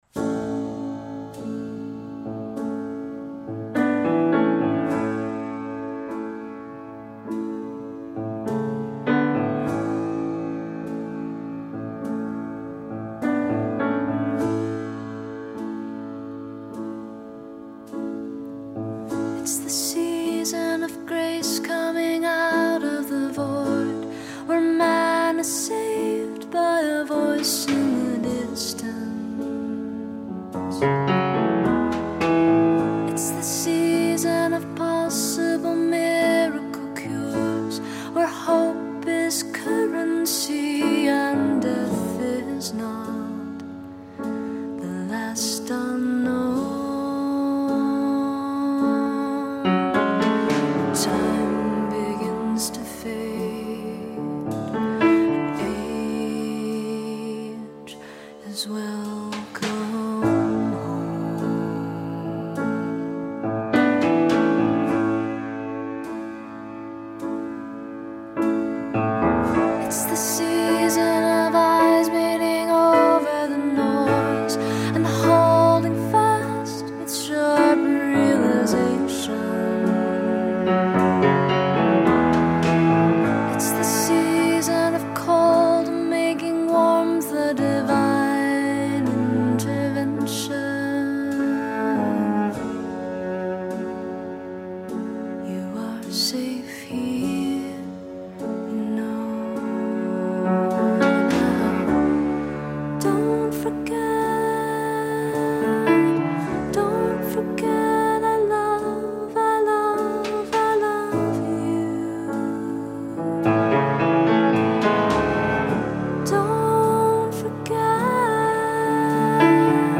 Something quiet and introspective - why not.